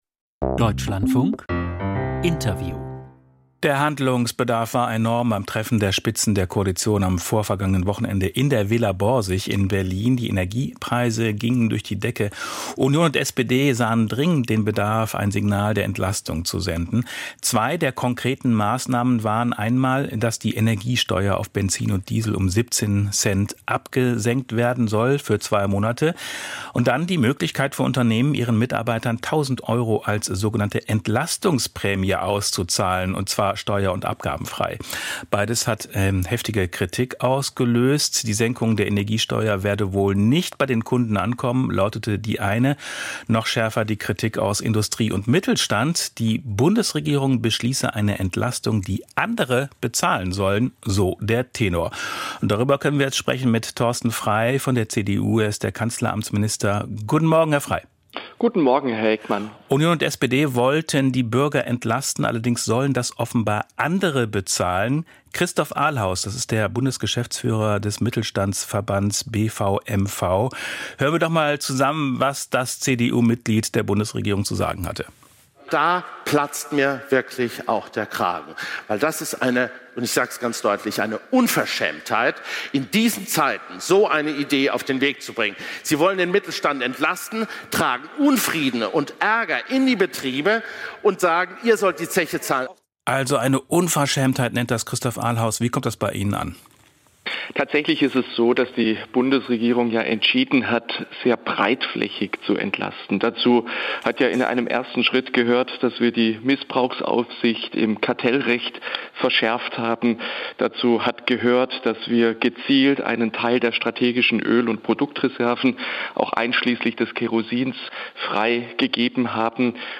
Kritik an Entlastungsprämie: Interview Kanzleramtsminister Thorsten Frei, CDU